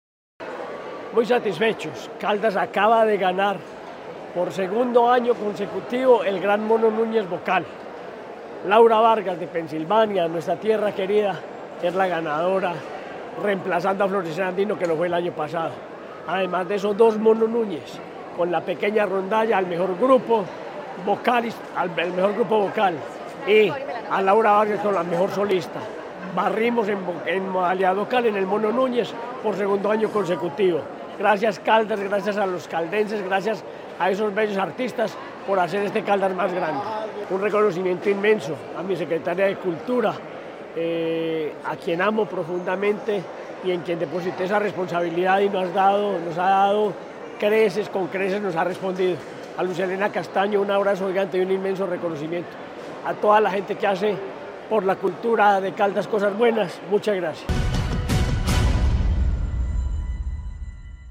Henry Gutiérrez Ángel, gobernador de Caldas